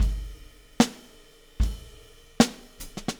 Weathered Beat Fill 02.wav